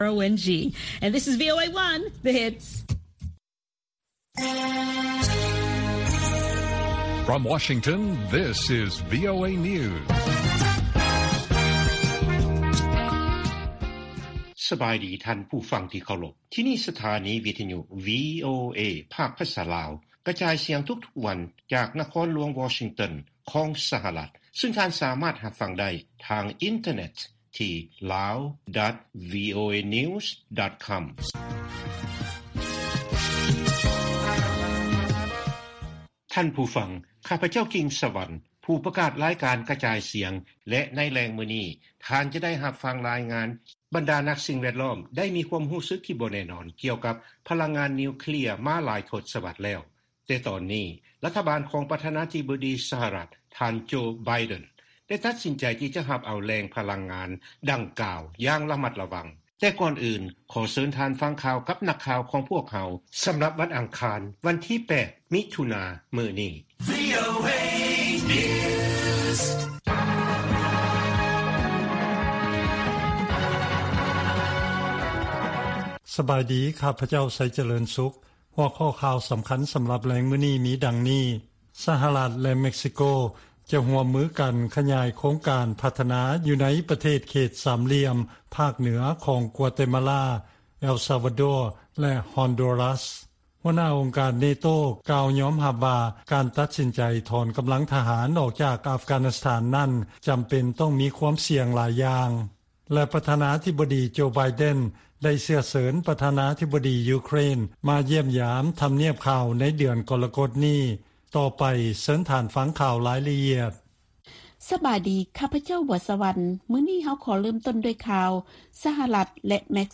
ວີໂອເອພາກພາສາລາວ ກະຈາຍສຽງທຸກໆວັນ. ຫົວຂໍ້ຂ່າວສໍາຄັນໃນມື້ນີ້ມີ: 1) ວິສາຫະກິດຮ່ວມມືລາວ-ຈີນ ວາງແຜນການຜະລິດ ດ້ານກະສິກຳຄົບວົງຈອນ ເພື່ອທີ່ຈະສົ່ງອອກໄປຈີນ.